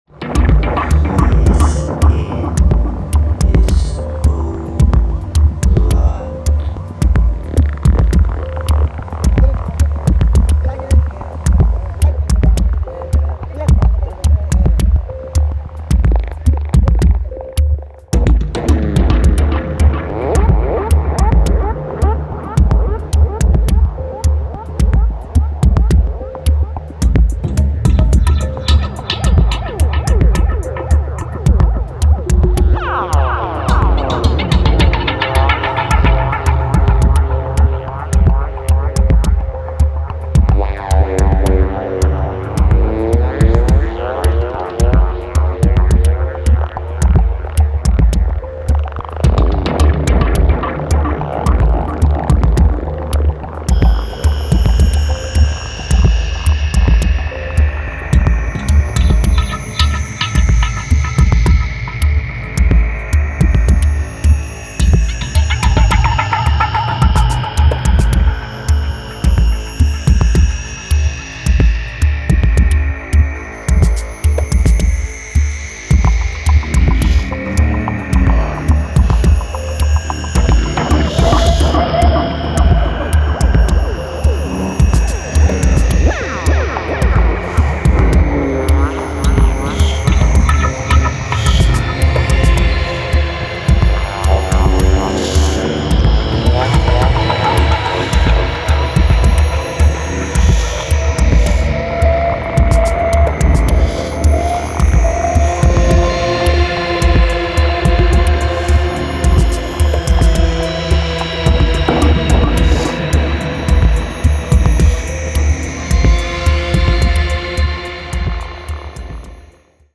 ダークに蠢くローエンドを基調に
108BPMまでグッとテンポを落とした暗黒ディスコ・ダブ
タイトでテンション高いグルーヴが揃いました。